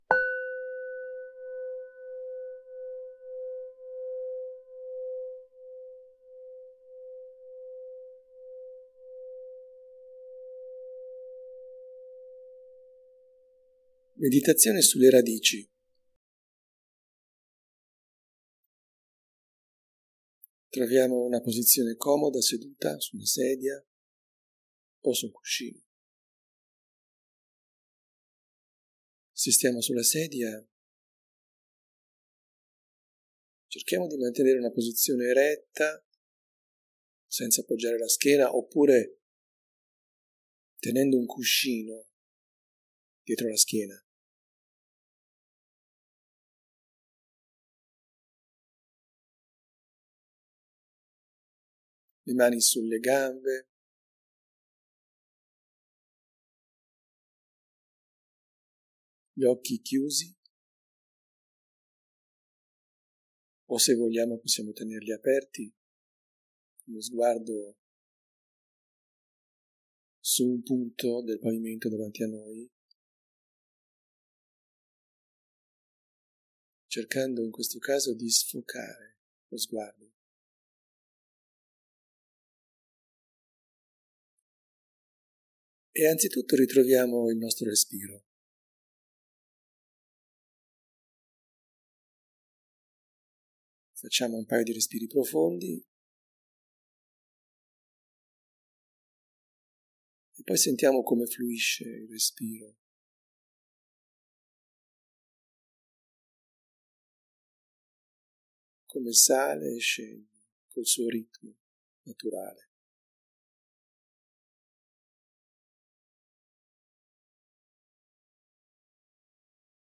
meditazioni guidate